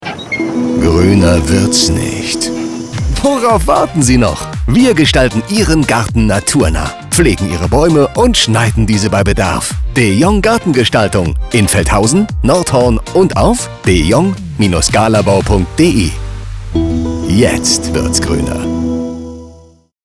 Hast du uns im Radio gehört?
De-Jong-Gartengestaltung-Radio-21.mp3